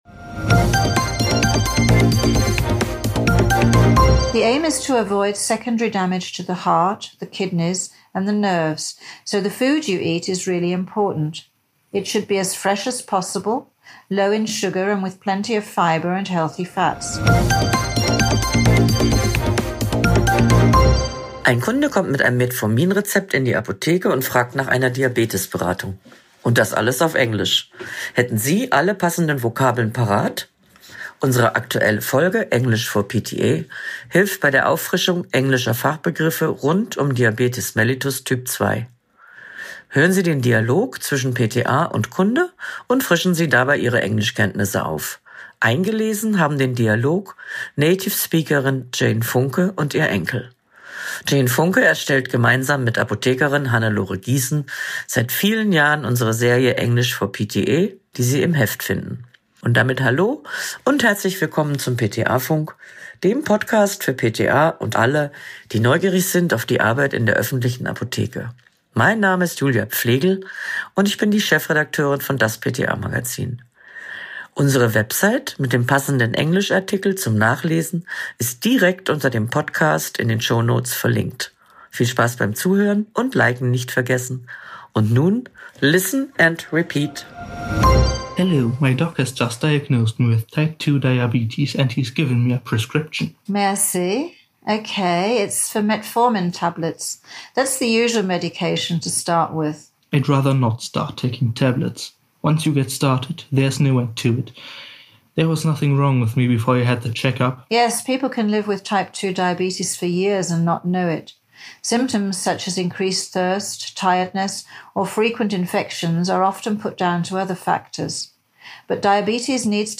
Hören Sie den Dialog zwischen PTA und Kunde und